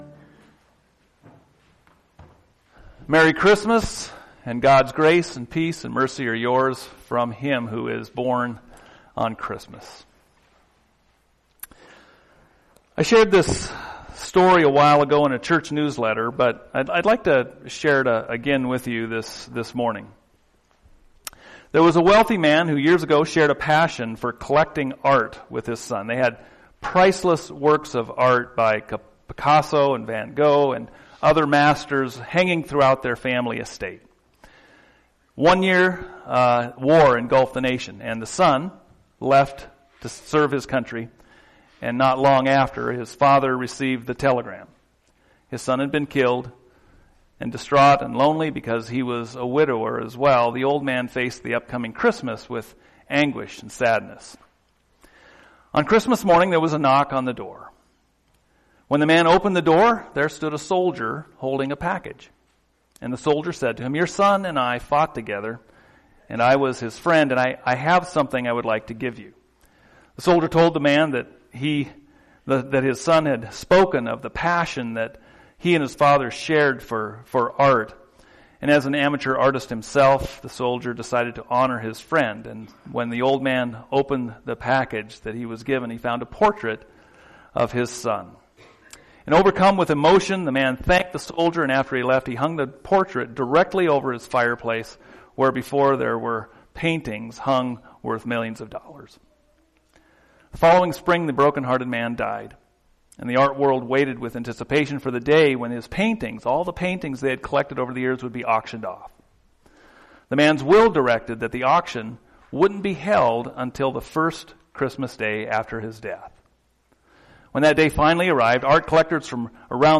Advent Sermons